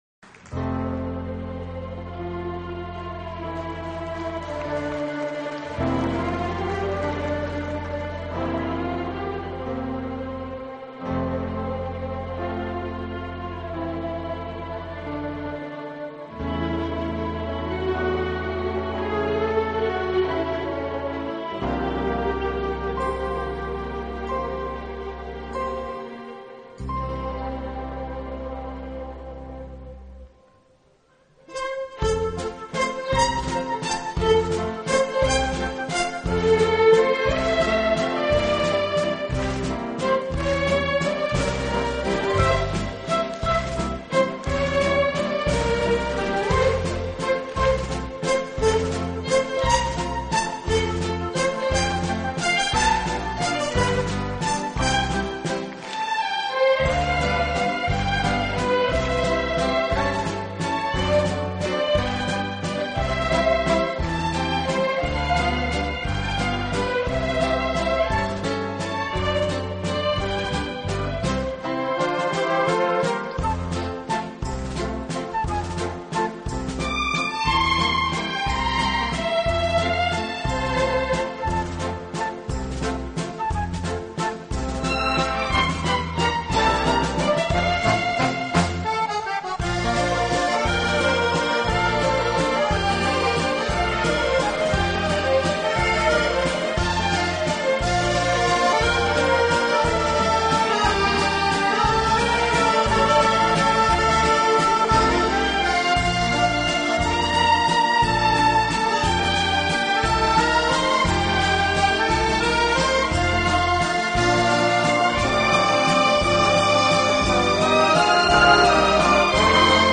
以热烈的旋律，独特的和声赢得千百万听众
此外，这个乐队还配置了一支训练有素，和声优美的伴唱合唱队。